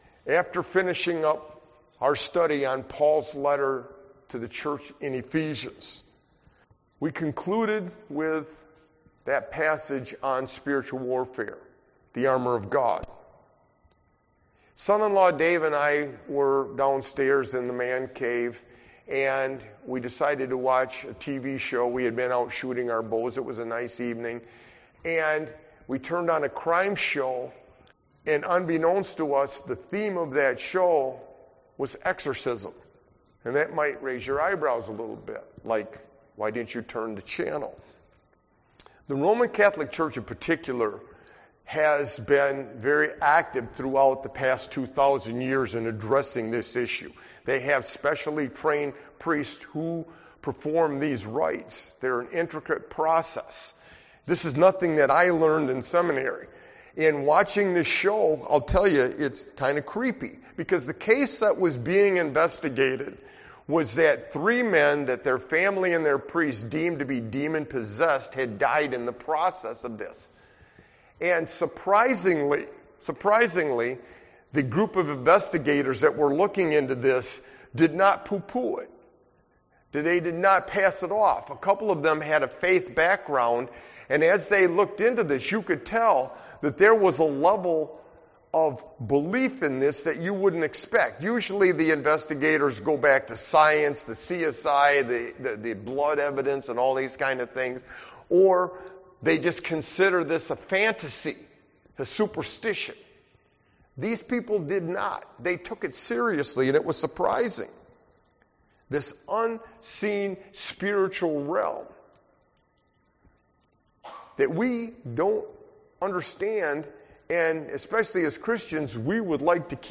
Sermon-How-Jesus-confronted-Spiritual-Darkness-61922.mp3